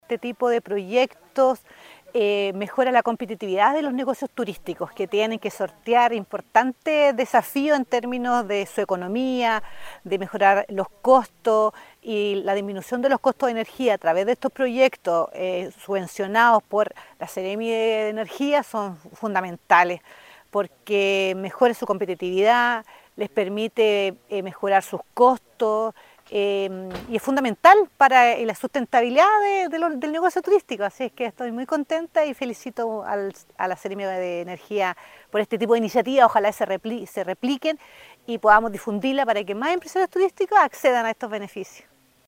Por su parte, la directora regional de Sernatur, Maritza San Martín, subrayó la relevancia de este programa para el sector turístico.